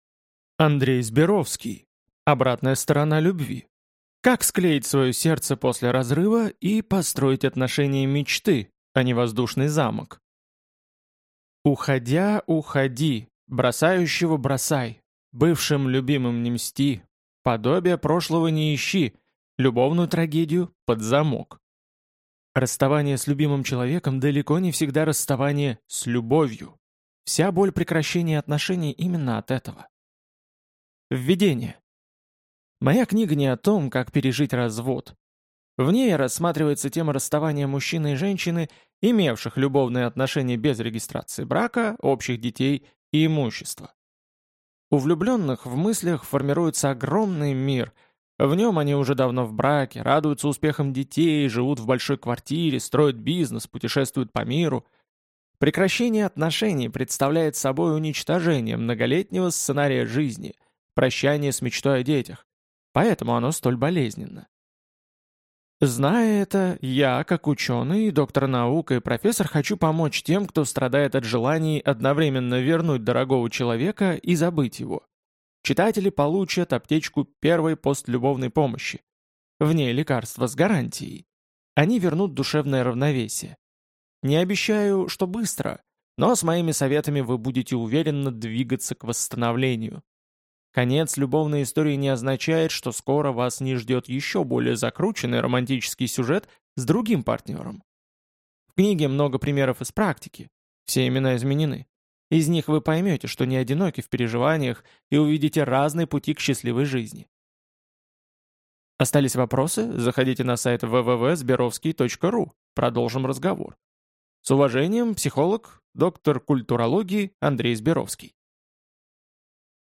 Аудиокнига Обратная сторона любви. Как склеить свое сердце после разрыва и построить отношения мечты, а не воздушный замок | Библиотека аудиокниг